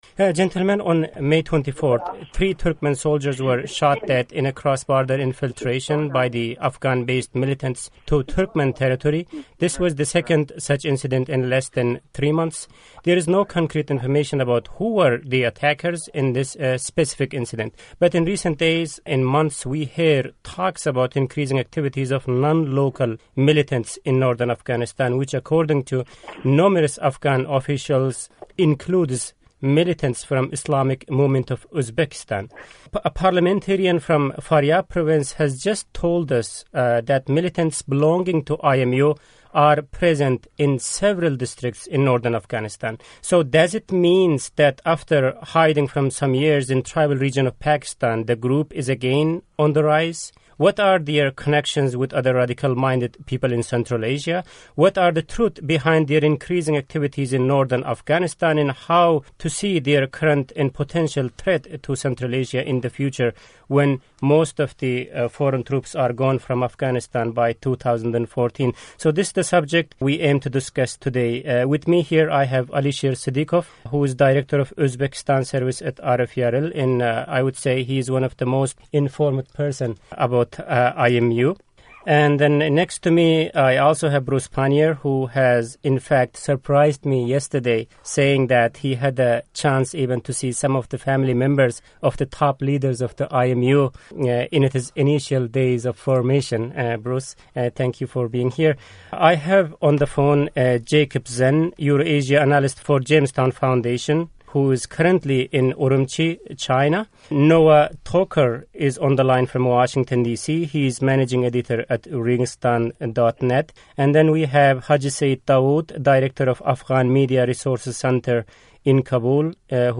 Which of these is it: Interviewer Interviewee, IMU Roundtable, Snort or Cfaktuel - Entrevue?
IMU Roundtable